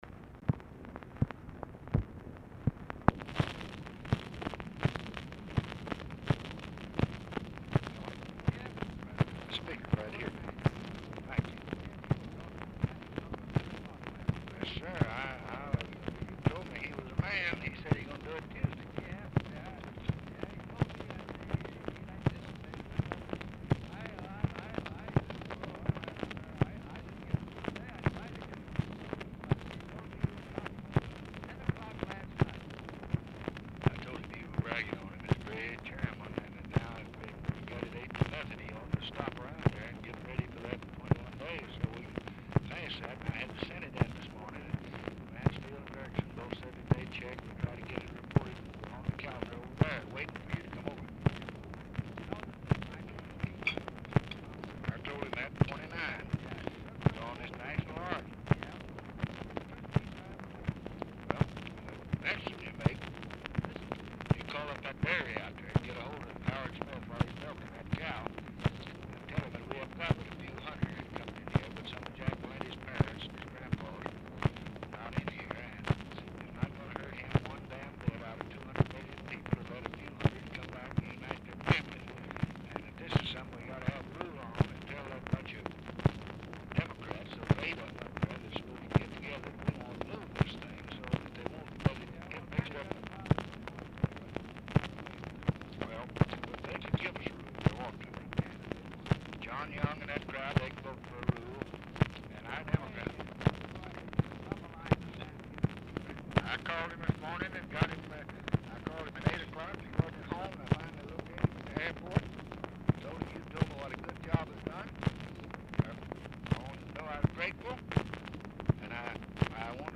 ALMOST INAUDIBLE DISCUSSION OF VOTE LAST NIGHT ON POVERTY BILL?, COMMITTEE VOTE ON IMMIGRATION BILL?; LBJ'S CALL TO SAM GIBBONS?; LBJ INVITES MCCORMACK TO LBJ RANCH; GEORGE FELDMAN'S APPOINTMENT AS AMBASSADOR TO MALTA; HIGHER EDUCATION, TAFT-HARTLEY BILLS
Oval Office or unknown location
"ALL THESE BELTS OF POOR QUALITY - ALL INAUDIBLE" WRITTEN ON ENVELOPE CONTAINING DICTABELTS; FRANK THOMPSON, WILLIAM ANDERSON ARE MEETING WITH MCCORMACK AT TIME OF CALL
Telephone conversation
Dictation belt